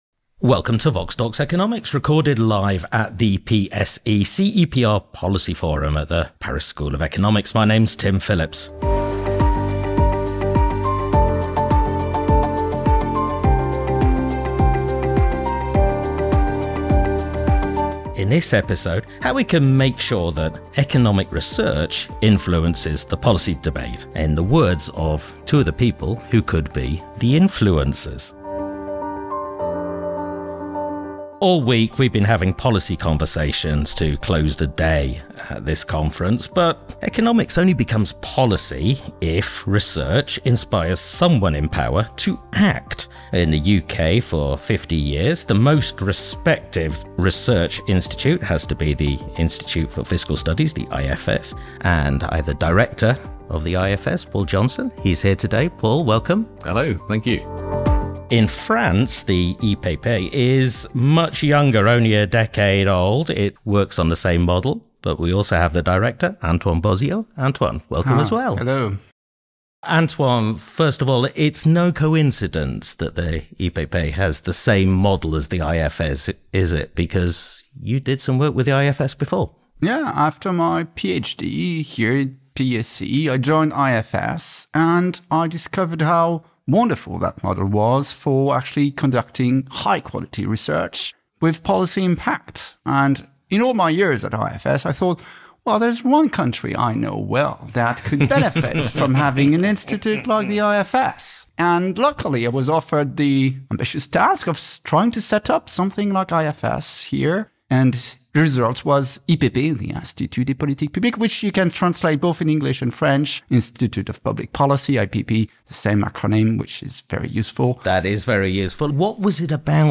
From the PSE-CEPR Policy Forum at the Paris School of Economics.